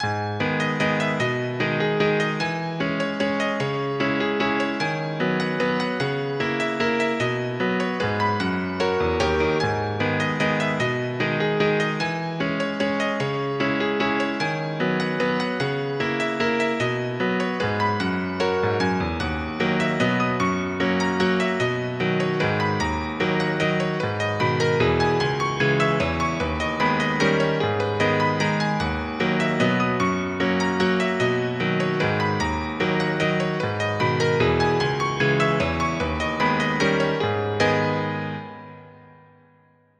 vals
triste
pianoforte
melancolía
melancólico
romanticismo
romántico